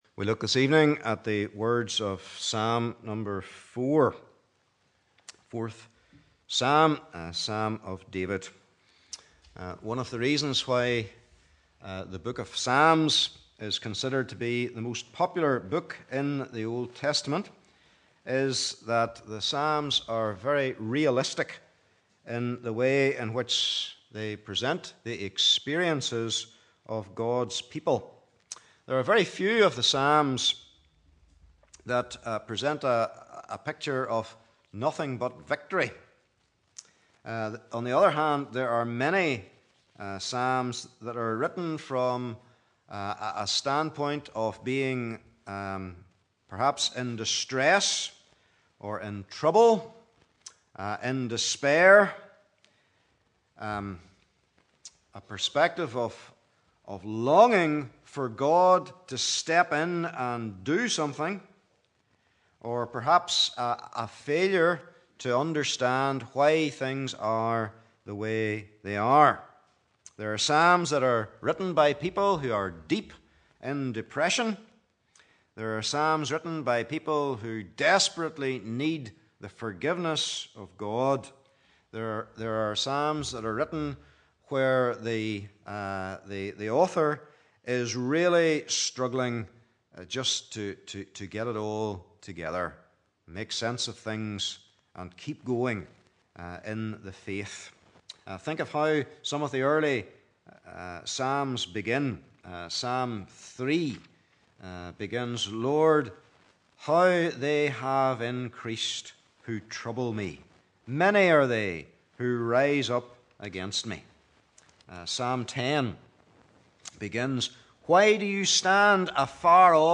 Service Type: Evening Service